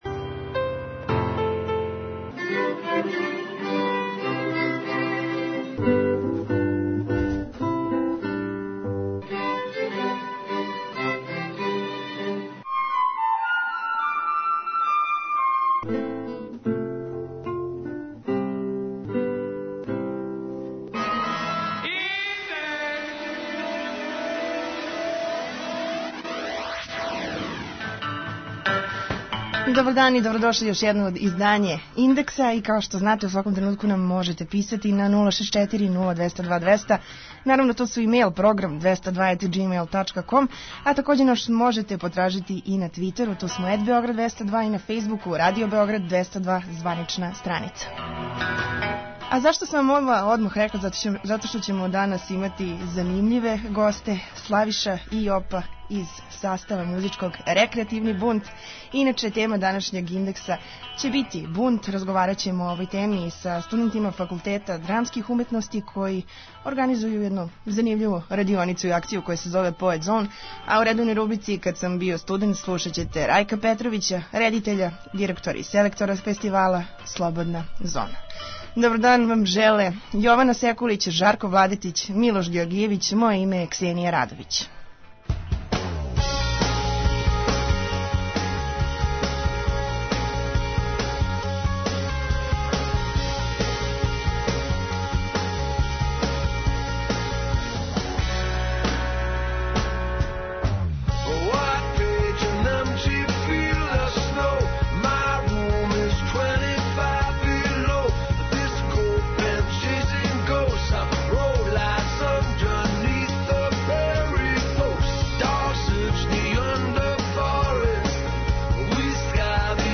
О бунту разговарамо са студентима Факултета драмских уметности у Београду, али и са члановима бенда Рекреативни бунт.